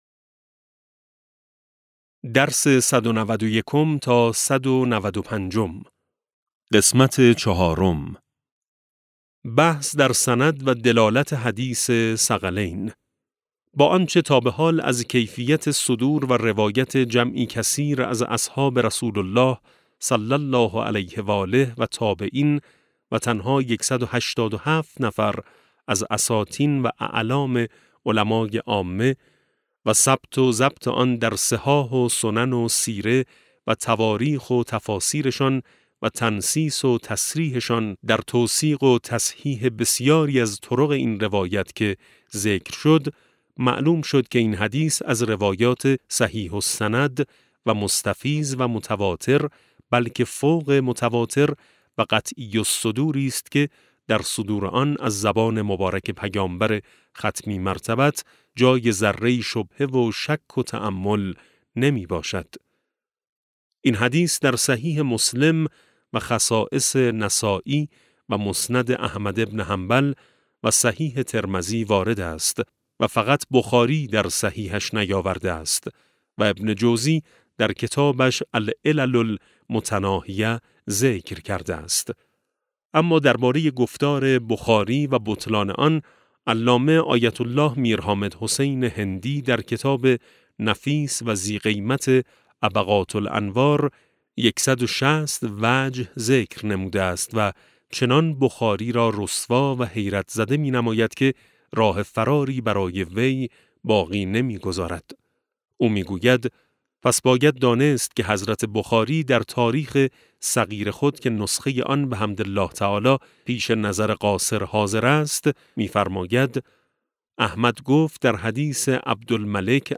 کتاب صوتی امام شناسی ج 13 - جلسه16